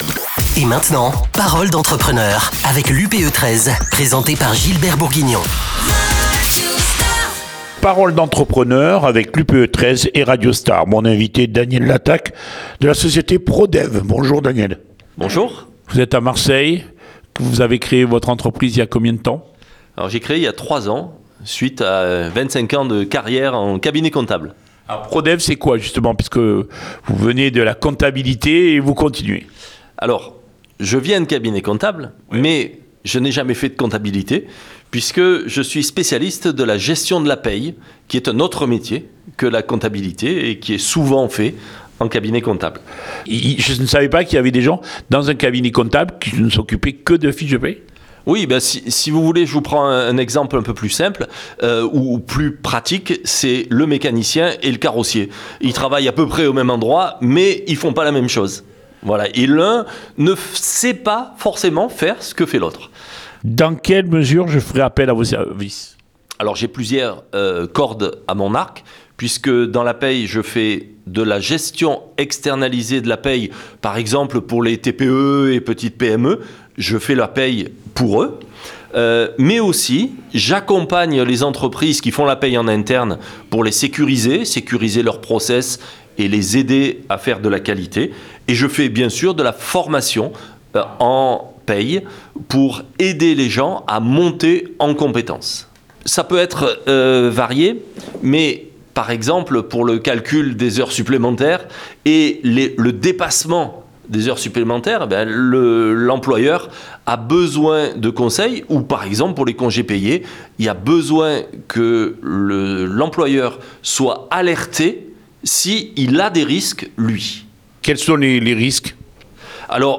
Un podcast du 22 décembre 2024 avec Radio Star dans les locaux de l’UPE 13